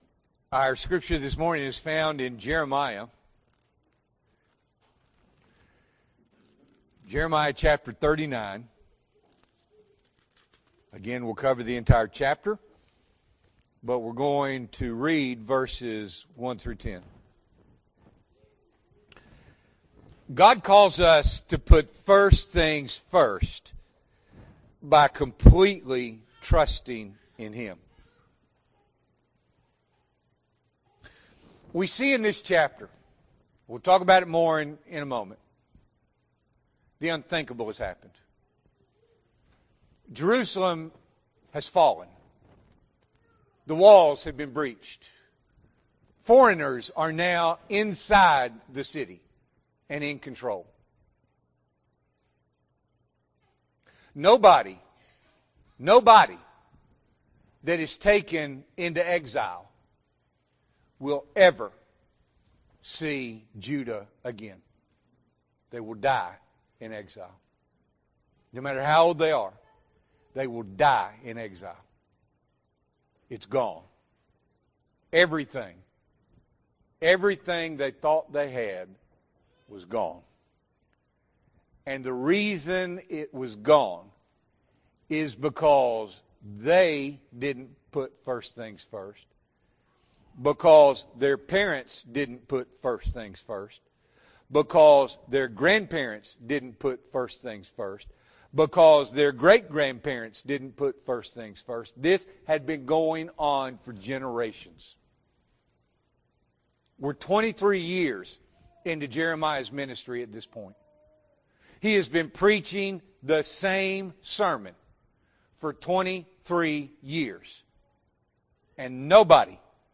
July 20, 2025 – Morning Worship